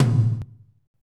Index of /90_sSampleCDs/Northstar - Drumscapes Roland/DRM_Techno Rock/TOM_F_T Toms x
TOM F T H0AL.wav